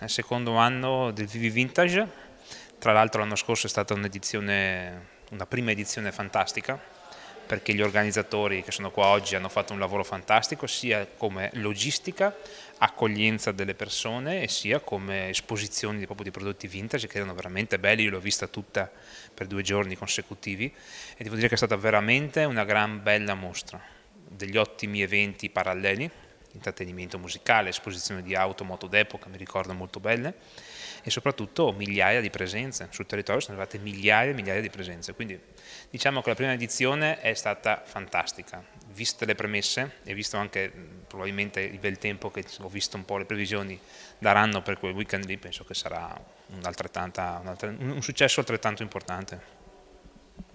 Alla conferenza stampa di presentazione dell’evento è intervenuto anche Filippo Gavazzoni, Assessore al Turismo di Peschiera del Garda:
Filippo-Gavazzoni-PICO.mp3